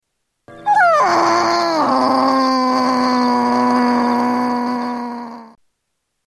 Moaning